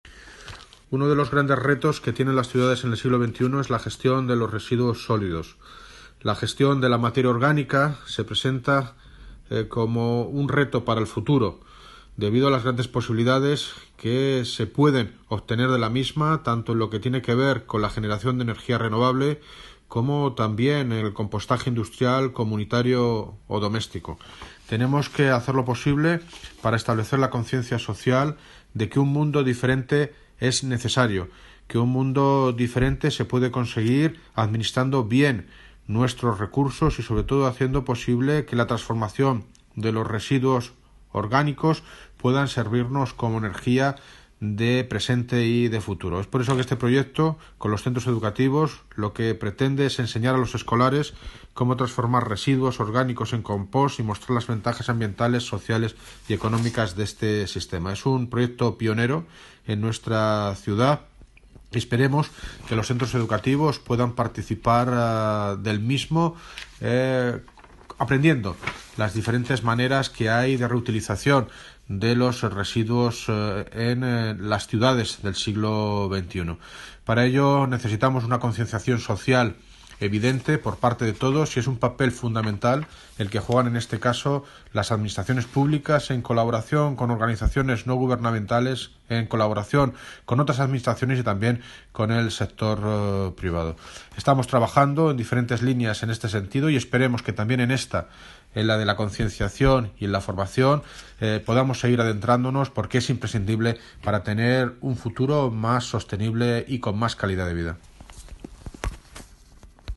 Sonido - David Lucas (Alcalde de Móstoles) Sobre campaña compostaje en colegios